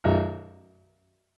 MIDI-Synthesizer/Project/Piano/3.ogg at 51c16a17ac42a0203ee77c8c68e83996ce3f6132